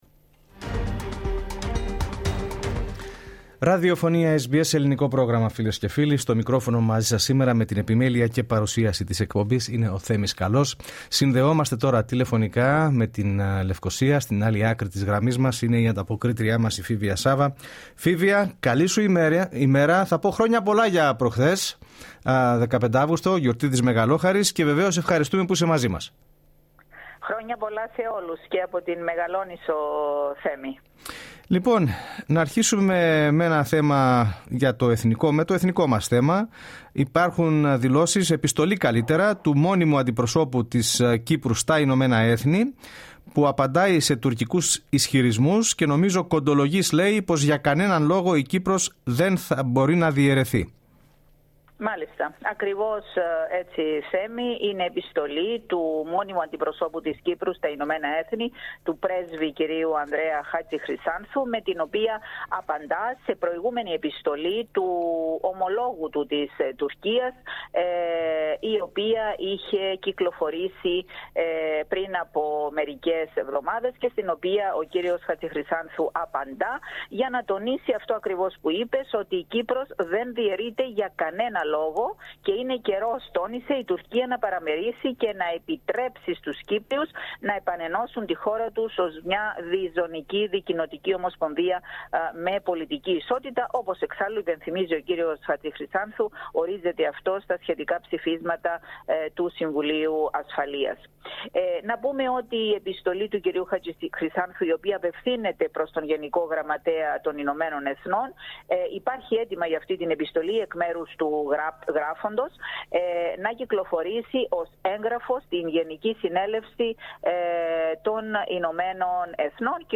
ανταποκριση-κυπρος-17-αυγουστου.mp3